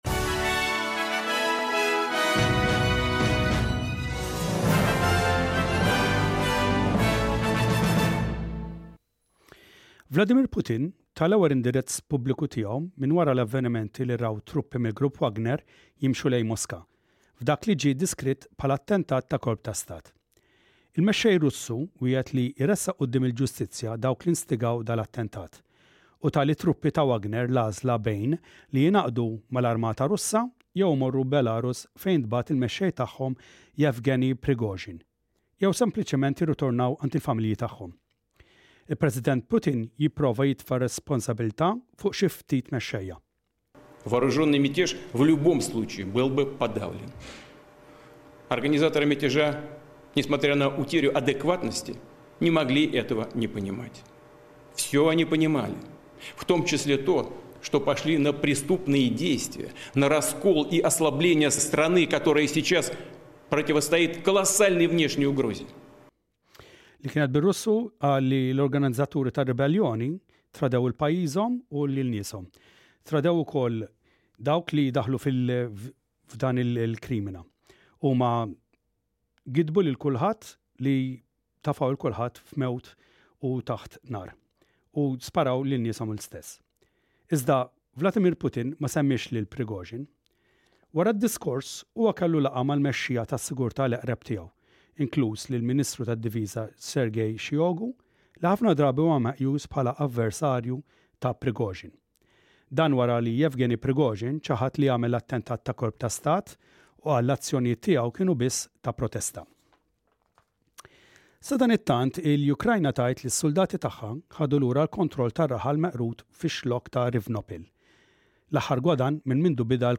SBS Radio | Maltese News: 27/06/23